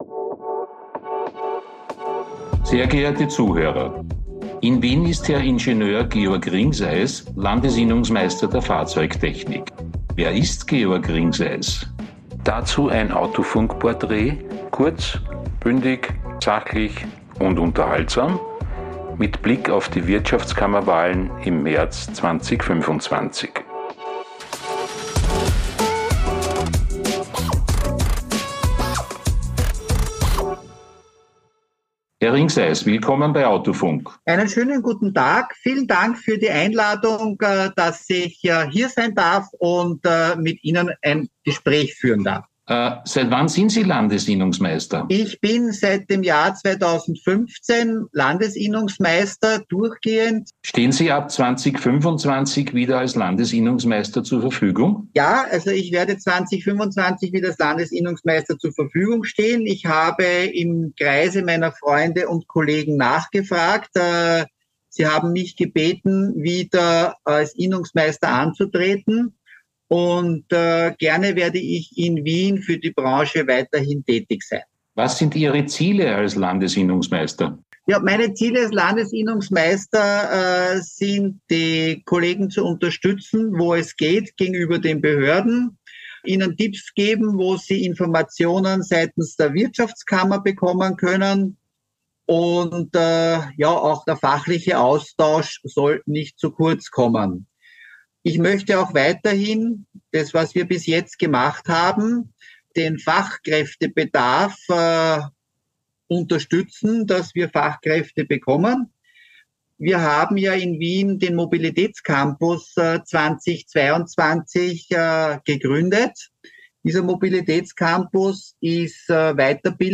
Kurz - Bündig - Saachlich - Unterhaltsam In der Sonderserie
im Gespräch.